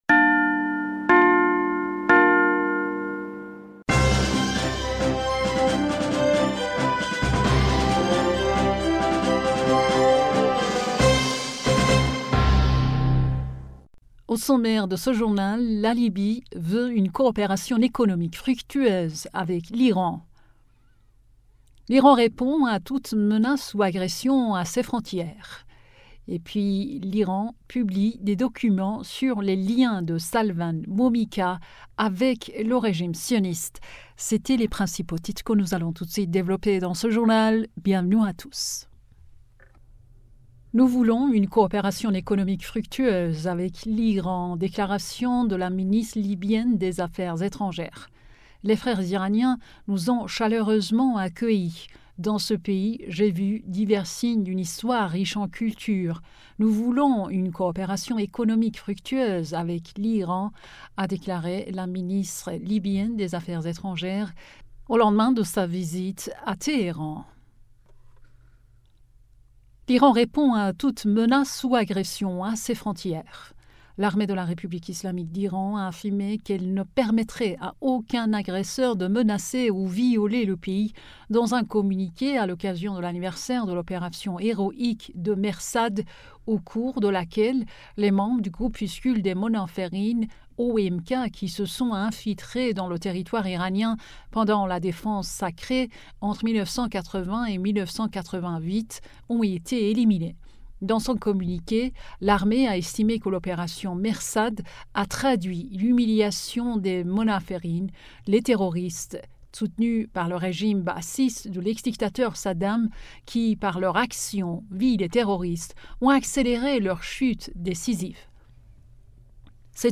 Bulletin d'information du 26 Juillet 2023